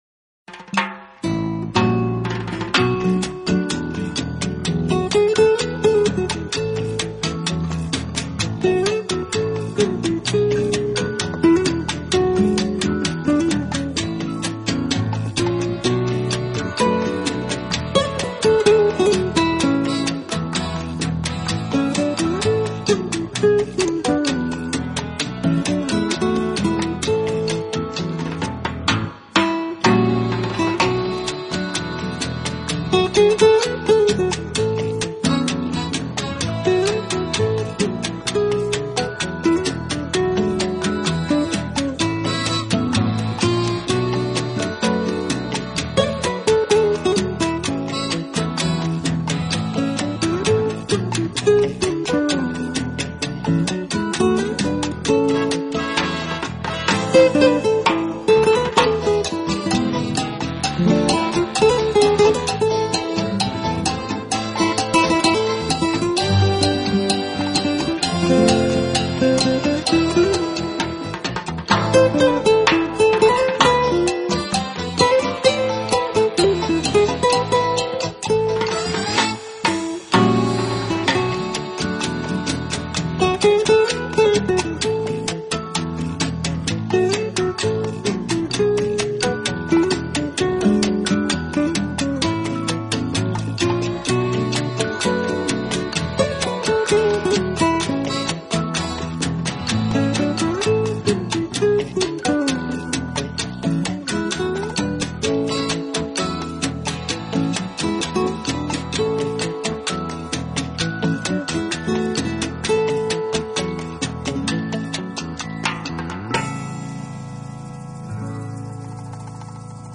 音乐流派： Jazz-Pop、Fusion、Crossover Jazz
城市中轻快的节奏，配上性感的Keyboard吉他伴奏进入不夜天!